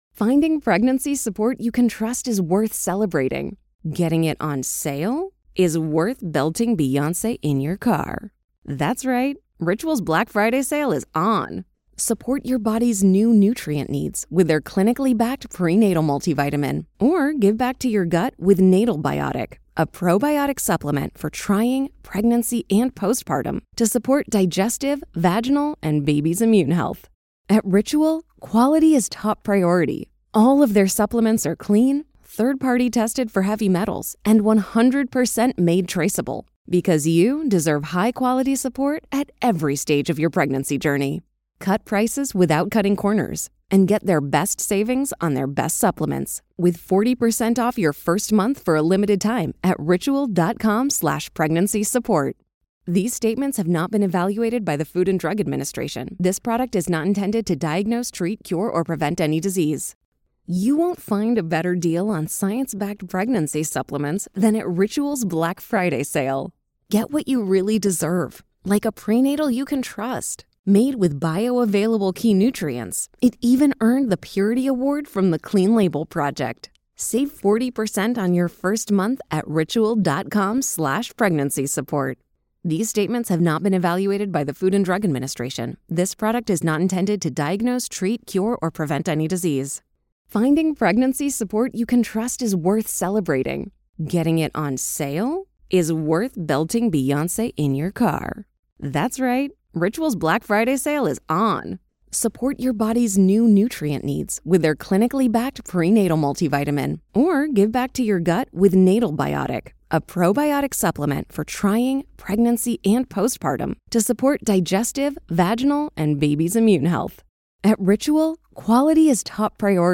Winner reflects on her decision, the weaponization of the Espionage Act, her years in prison, and the struggle to rebuild her life after release. It’s an unfiltered discussion about truth, power, and the personal cost of dissent in modern America.